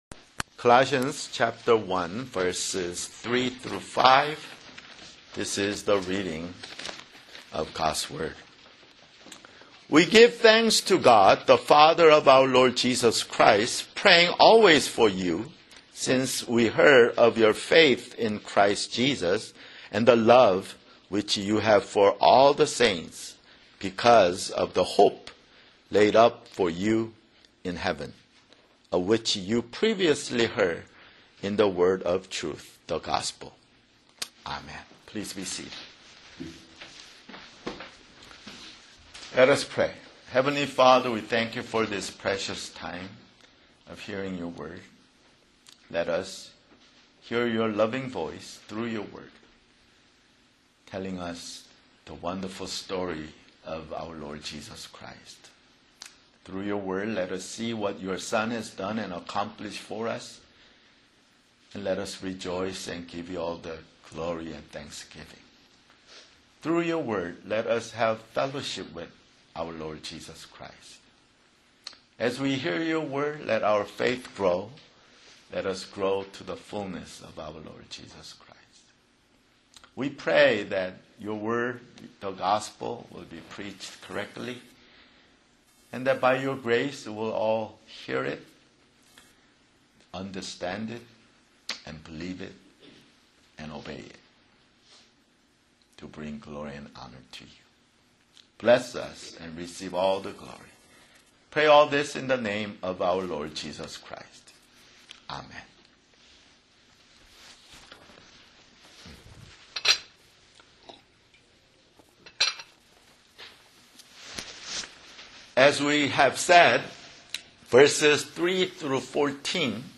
[Sermon] Colossians (10)